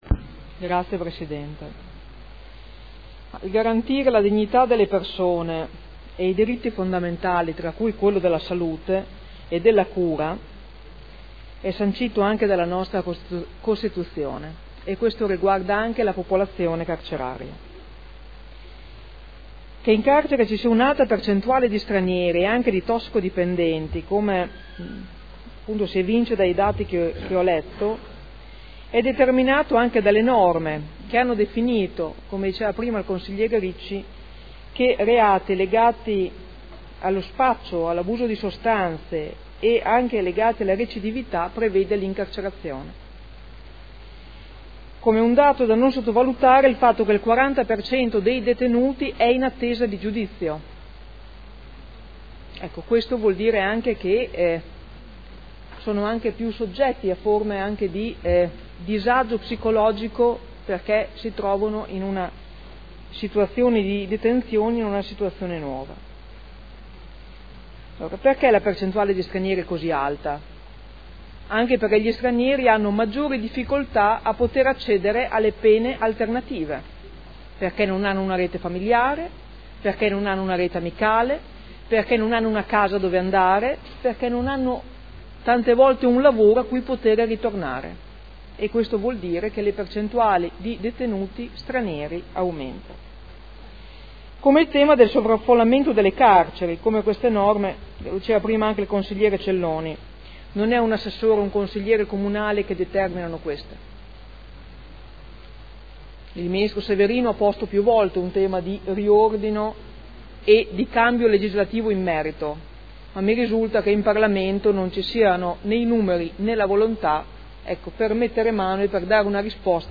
Francesca Maletti — Sito Audio Consiglio Comunale